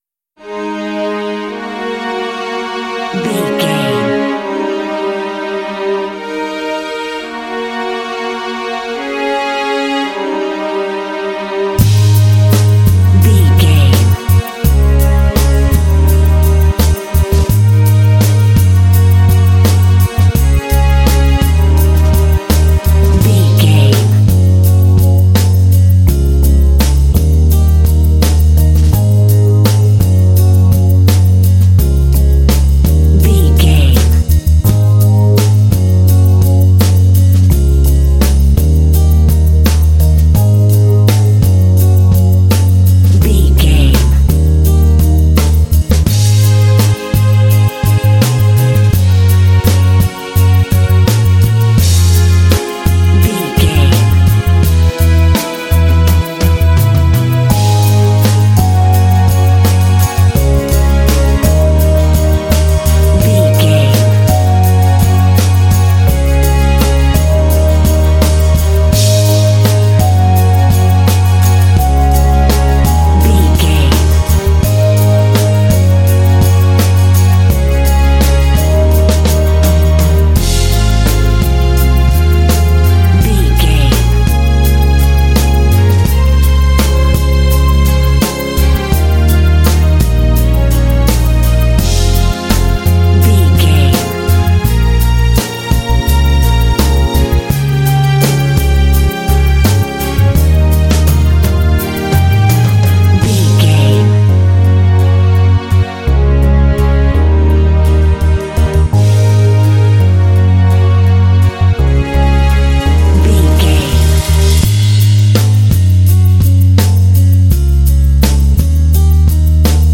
Relaxed, easy track, ideal for adventure games.
Uplifting
Mixolydian
A♭
relaxed
serene
mellow
soft
strings
drums
bass guitar
acoustic guitar
synthesiser
contemporary underscore